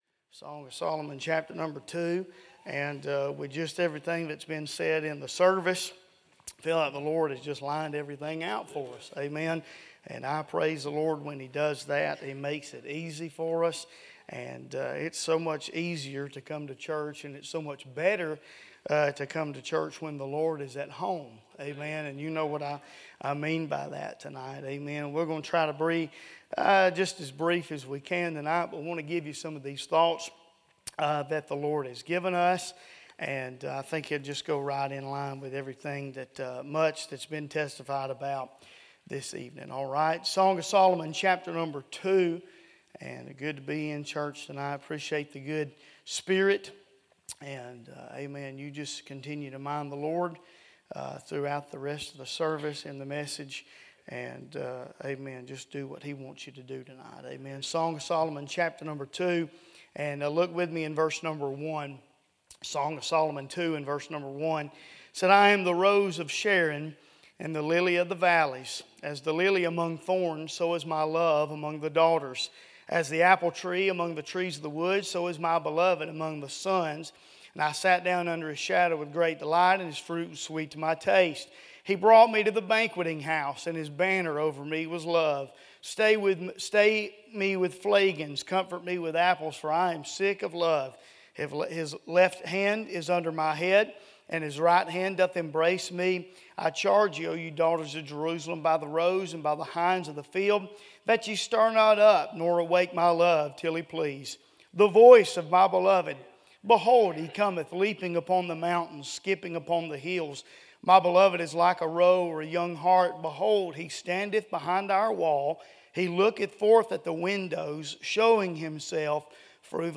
A sermon preached Wednesday Evening